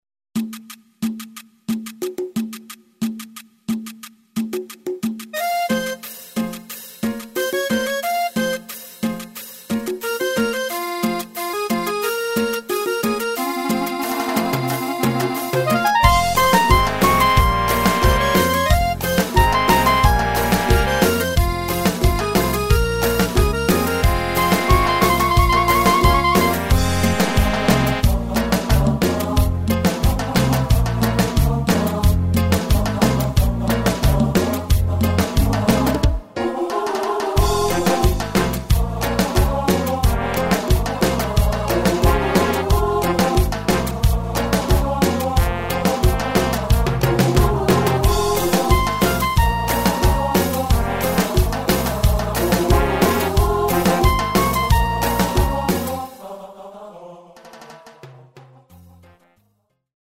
MIDI Multitrack
Bajo – 317 notas
Piano – 1115 notas
Acordeón – 136 notas
Trombón – 253 notas
Clarinete – 206 notas
Square Lead – 157 notas
Guitarra Eléctrica Jazz – 331 notas
Coros – 212 notas
Percusión – 2974 notas
Strings – 330 notas